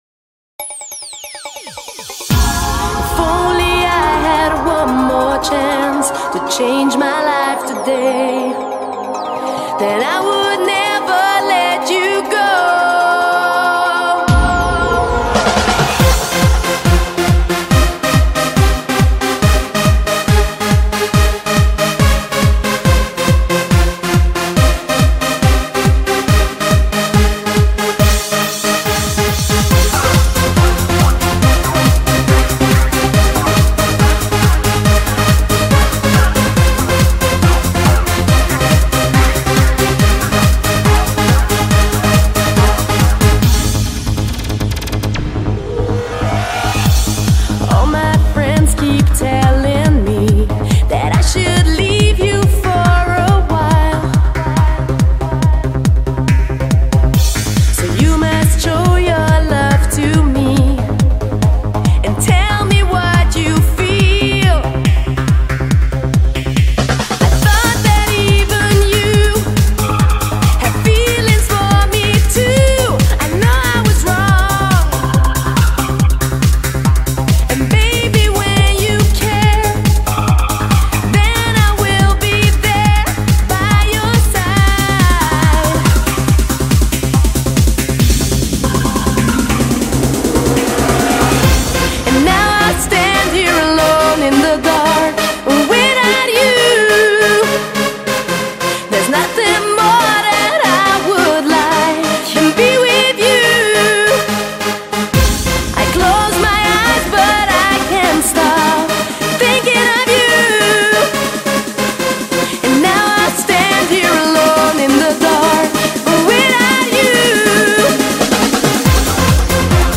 BPM140
Audio QualityMusic Cut
GENRE: VOCAL TRANCE